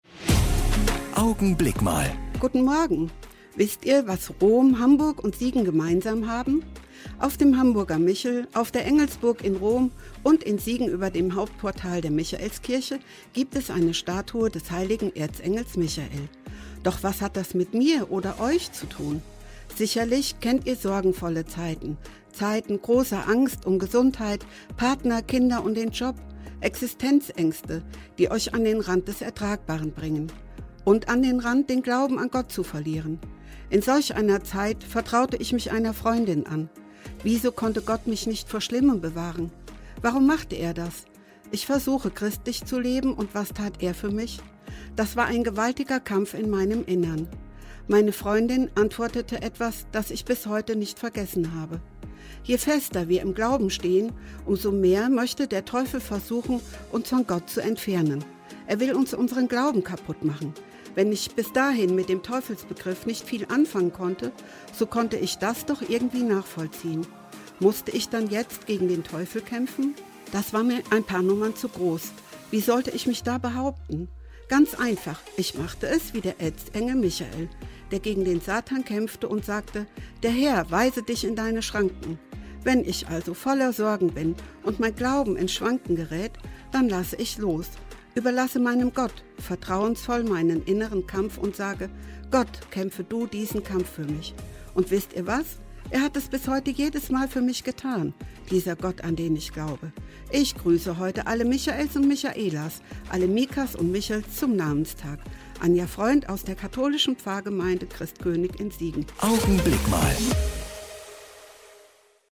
Augenblick Mal - die Kurzandacht im Radio
Jeden Sonntag gegen halb neun bei Radio Siegen zu hören: Die Kurzandacht der Kirchen (evangelisch und katholisch) - jetzt auch hier im Studioblog zum Nachhören.